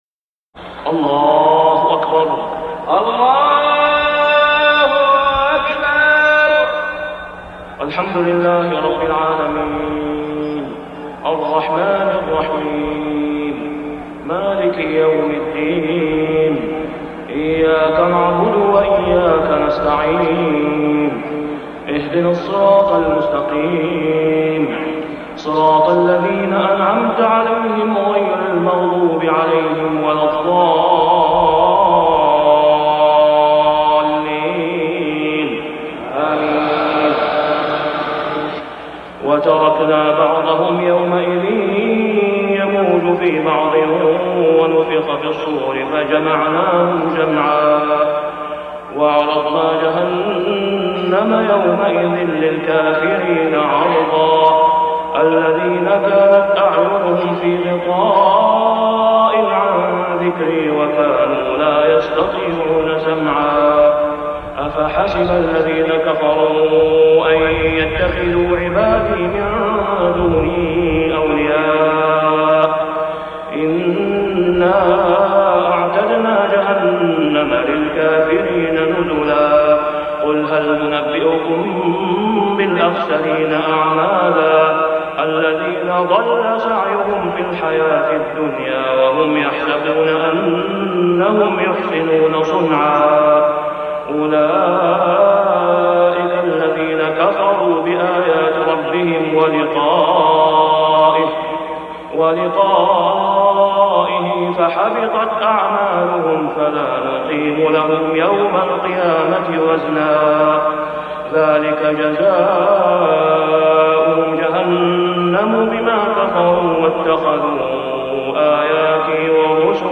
صلاة العشاء ( العام مجهول ) | ماتيسر من سورة الكهف 99-110 | > 1419 🕋 > الفروض - تلاوات الحرمين